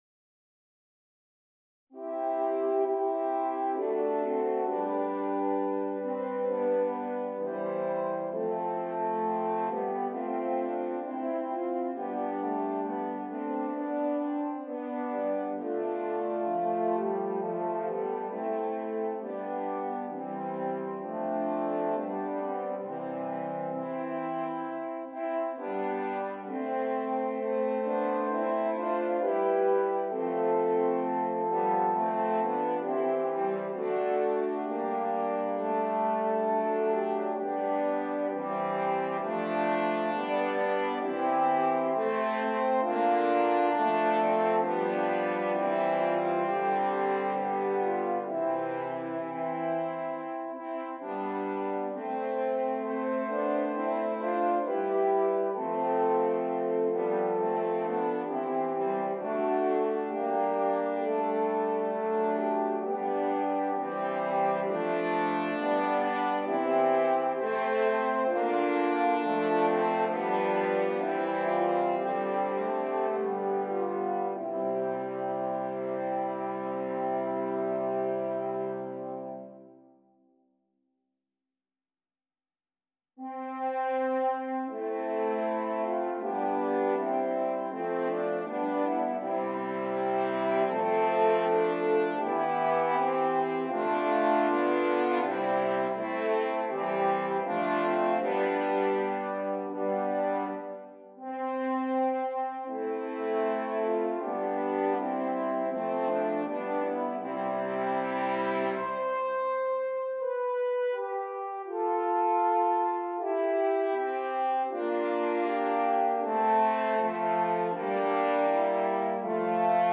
4 F Horns